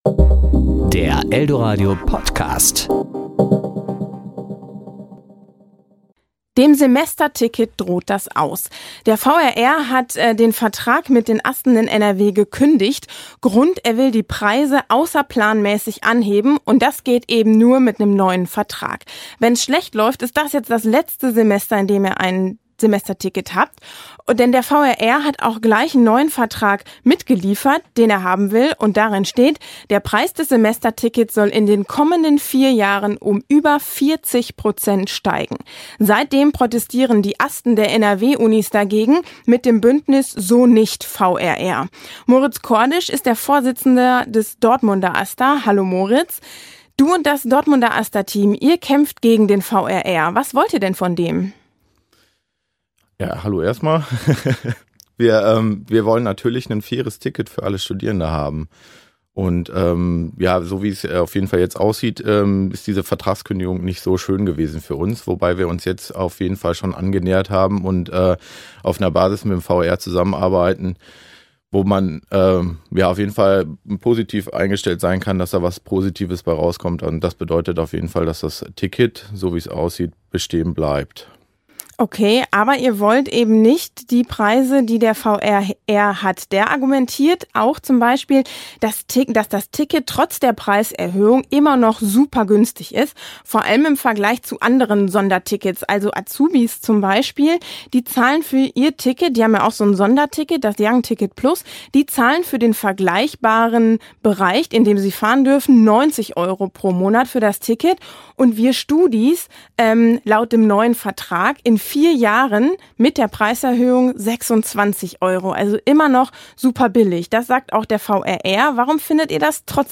Serie: Interview